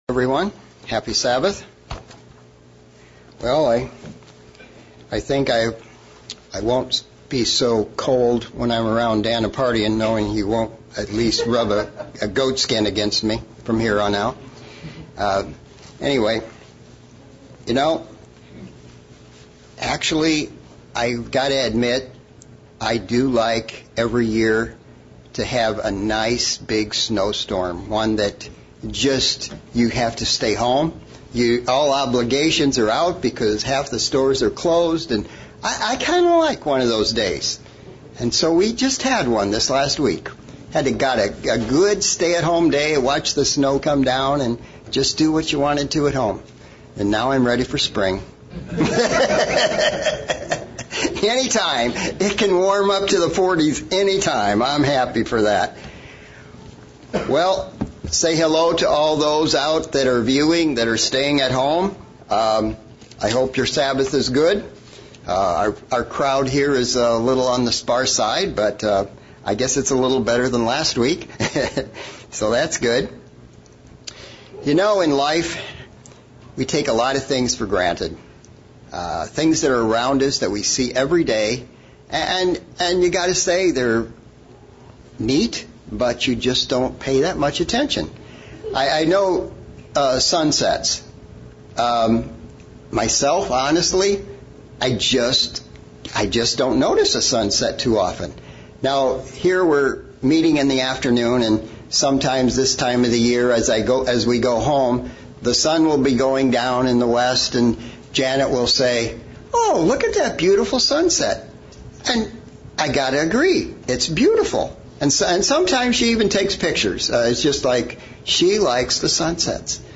Sermon looking at the use of prayer in the Bible and how we can use it as an effective tool to draw closer to God.